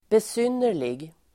Uttal: [bes'yn:er_lig]